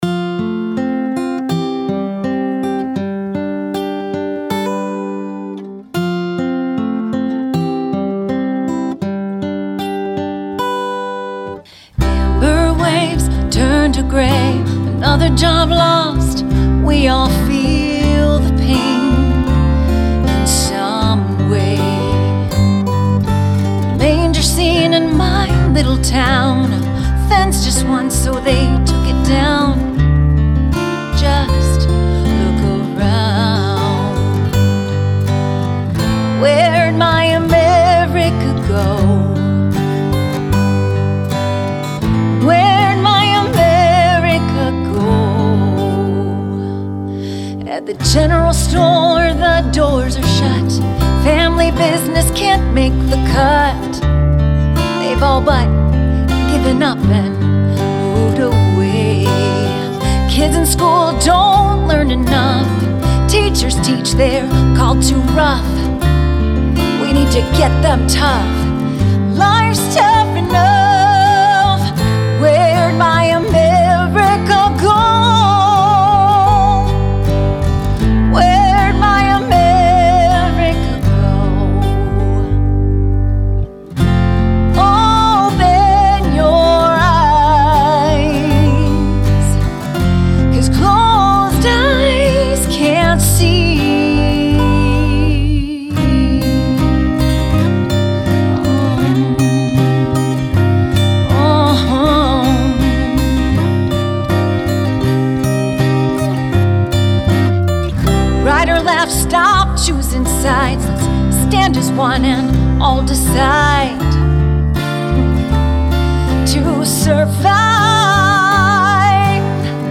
And, with her dynamic vocals and meanigful music.